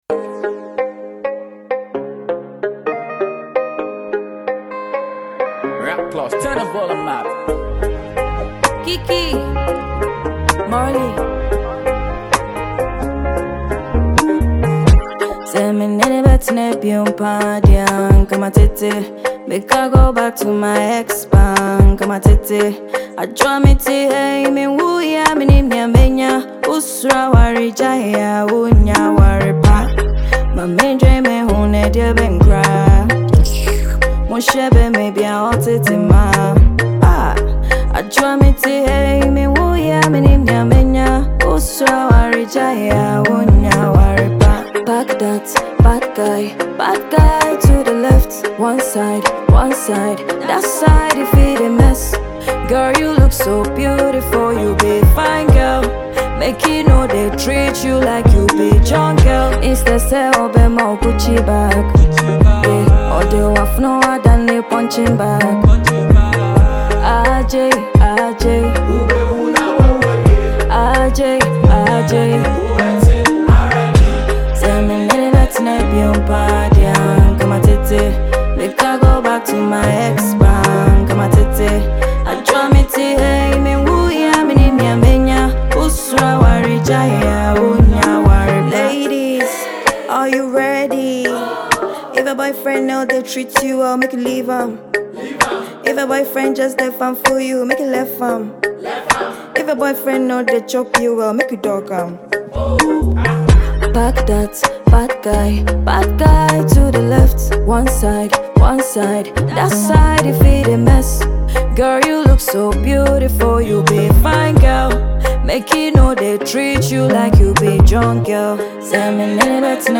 Ghanaian talented Female Singer